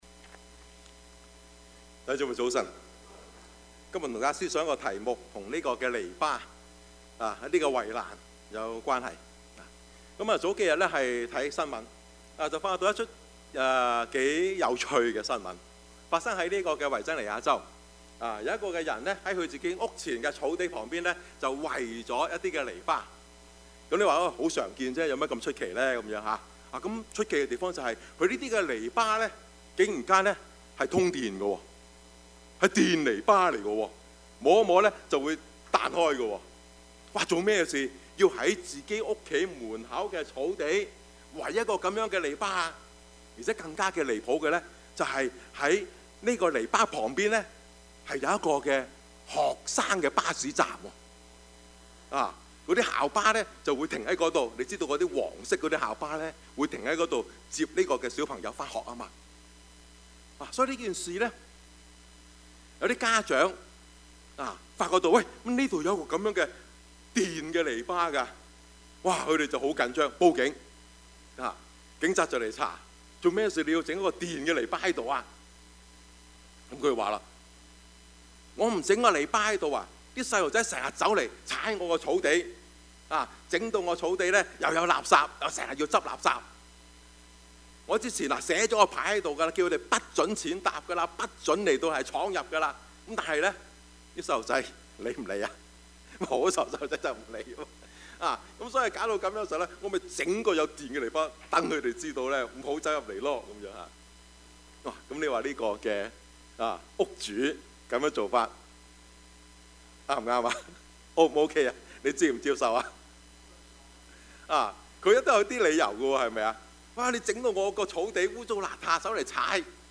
Passage: 可 7:24-37 Service Type: 主日崇拜
Topics: 主日證道 « 浮生若夢, 為歡幾何?